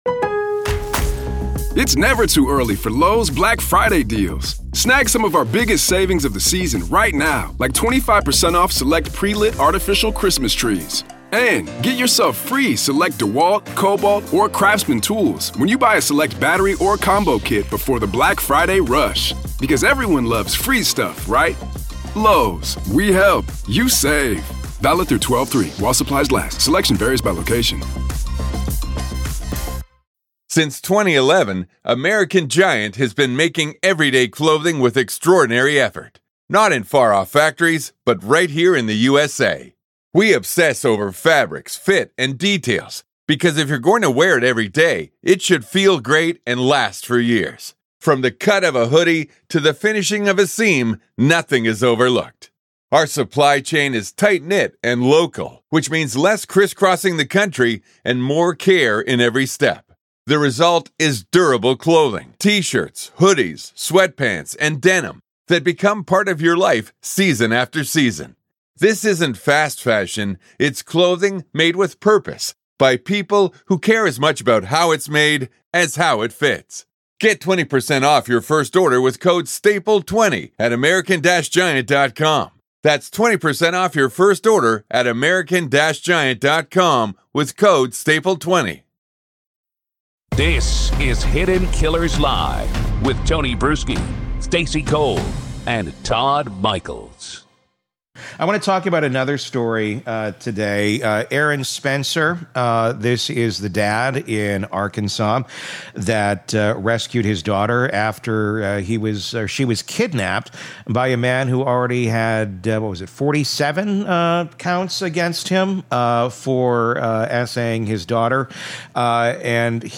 In this Hidden Killers interview